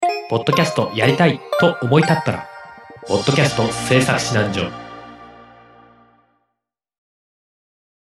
当サイトの音声CMです。